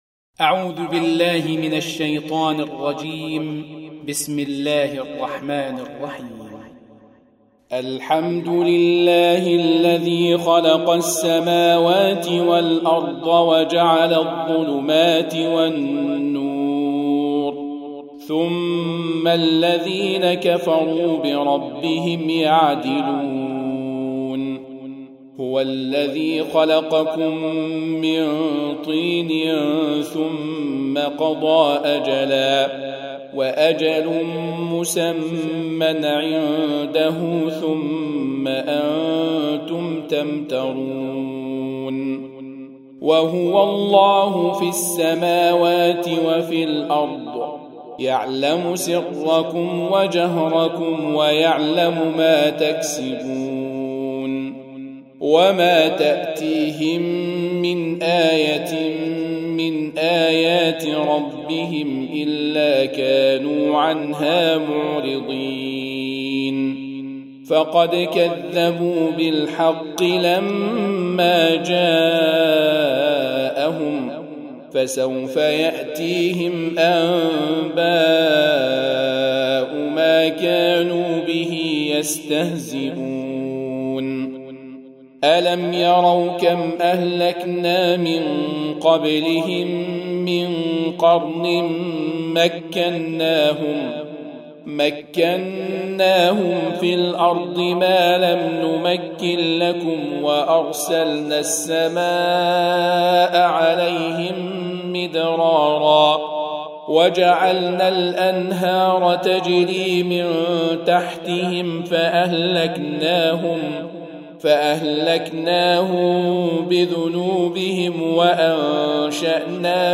Surah Repeating تكرار السورة Download Surah حمّل السورة Reciting Murattalah Audio for 6. Surah Al-An'�m سورة الأنعام N.B *Surah Includes Al-Basmalah Reciters Sequents تتابع التلاوات Reciters Repeats تكرار التلاوات